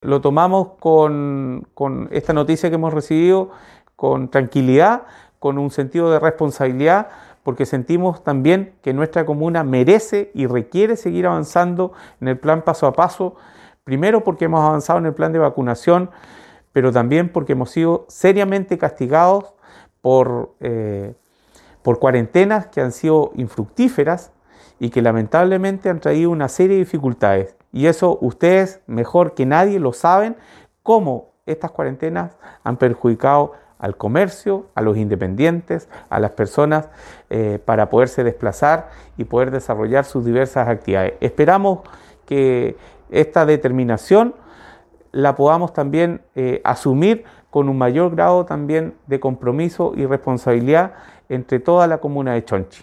En tanto el alcalde de Chonchi, Fernando Oyarzún, remarcó la importancia de tomar esta noticia con tranquilidad y responsabilidad.